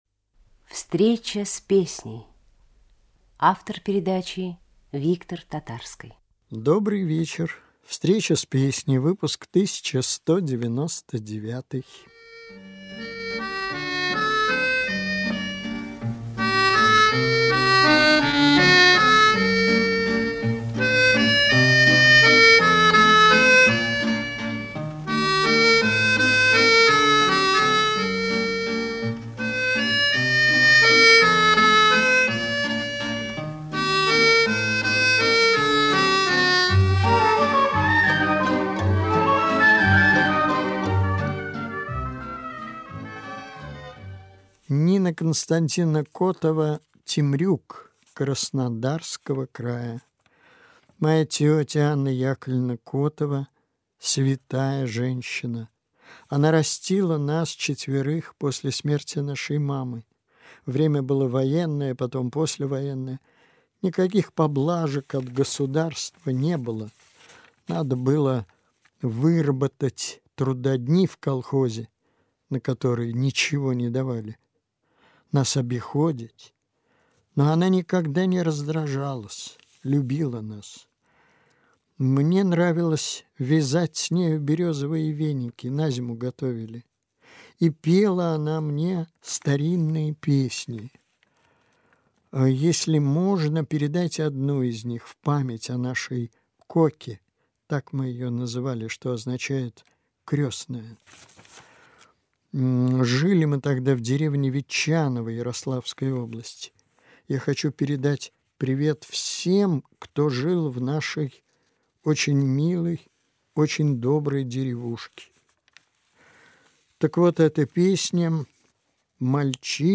русская песня
запись по трансляции